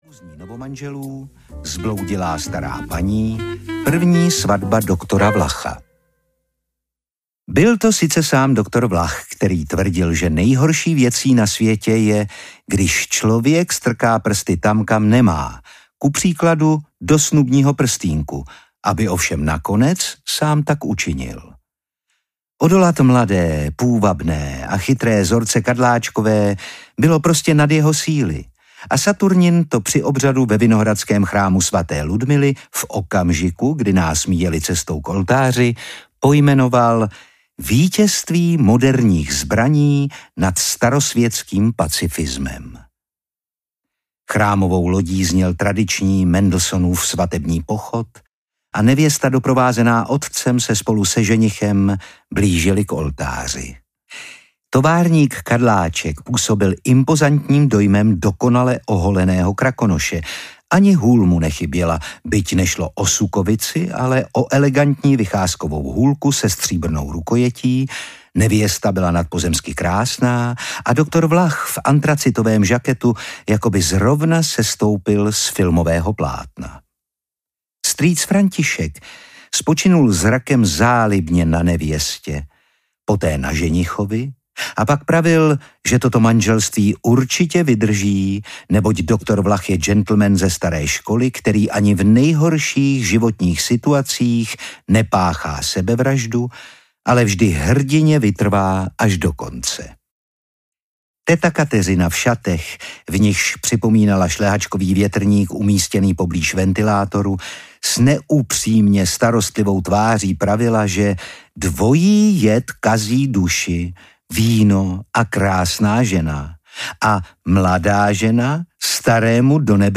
Saturnin zasahuje audiokniha
Ukázka z knihy
• InterpretOtakar Brousek ml.